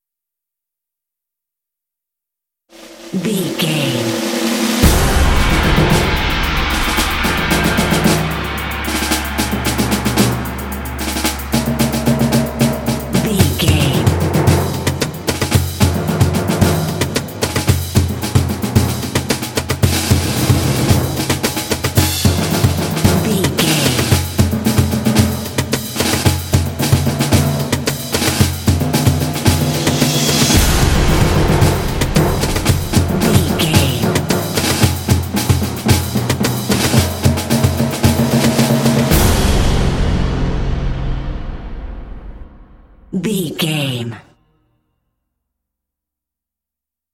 Aeolian/Minor
dramatic
foreboding
tension
drums
cinematic
film score